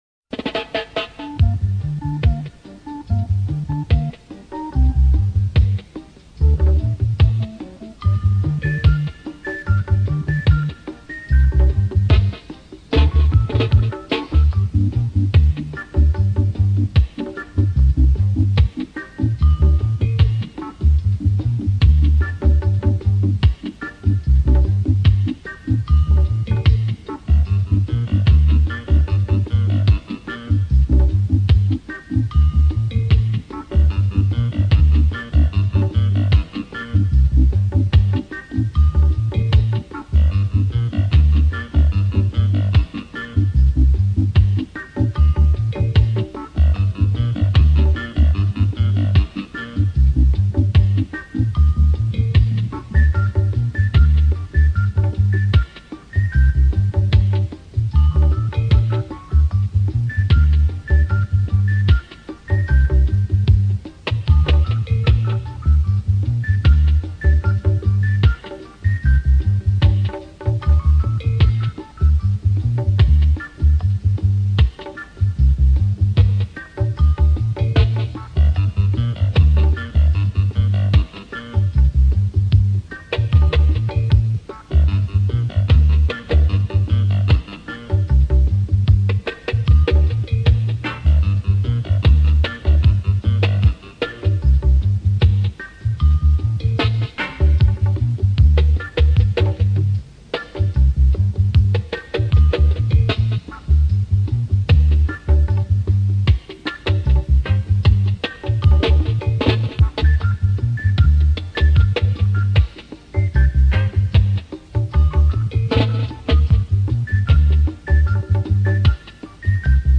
(Dub Version)